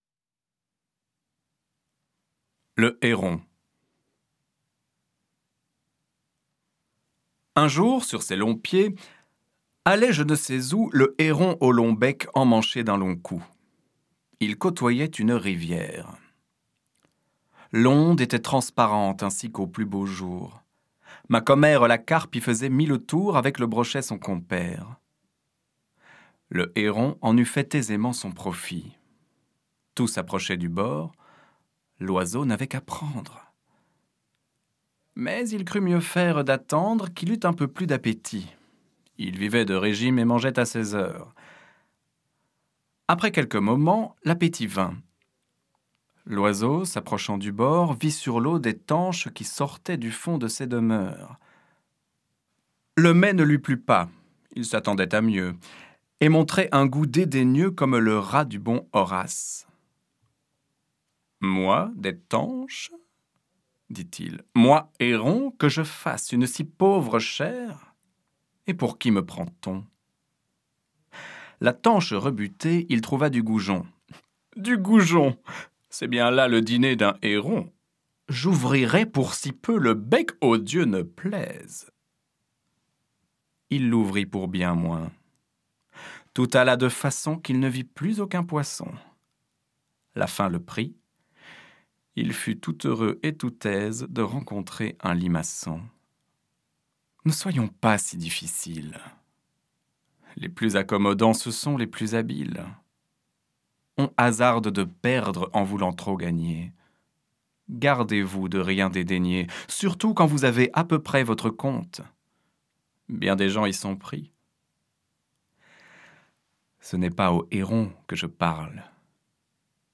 Fable